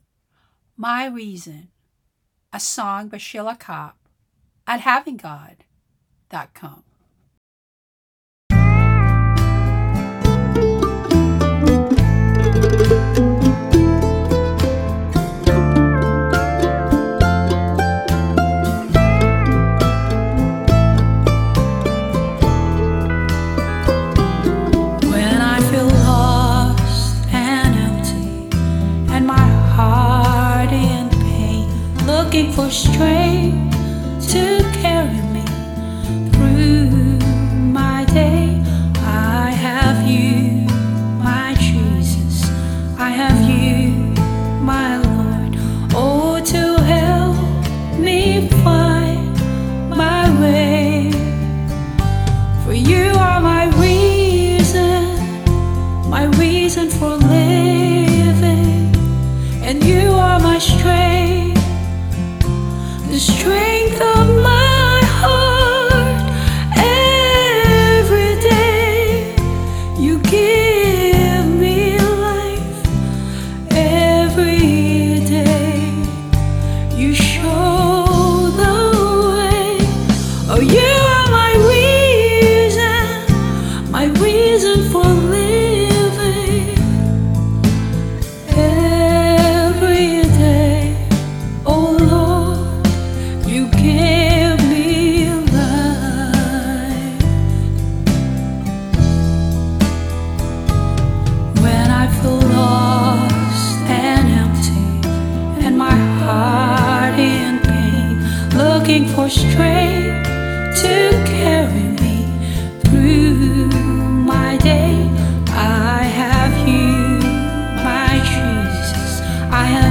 Vocals and Band-In-A-Box arrangement